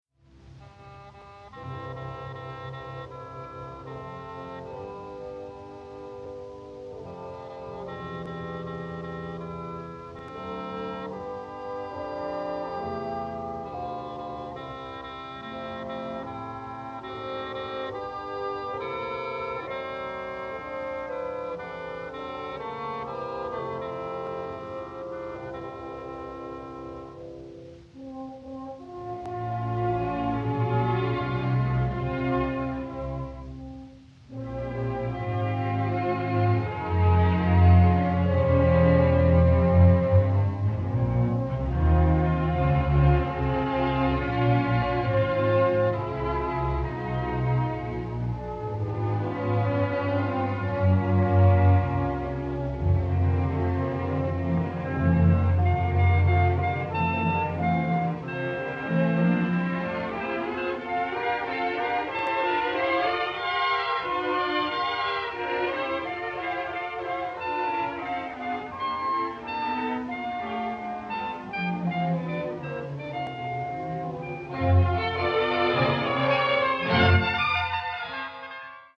The jovial spirit of the second song is similar to that of the main theme from Appalachia.
Both the theme from Appalachia and the second song are in a major key and have a similar melodic contour—the beginning of both melodies features an arpeggiated second-inversion tonic triad.